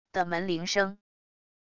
的门铃声wav音频